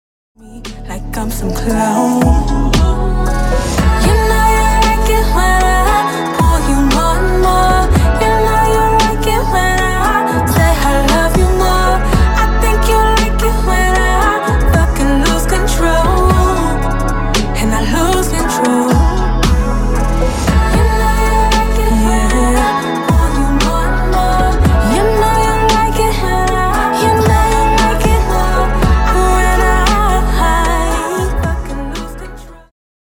Alternative RnB
Alternative-RnB.mp3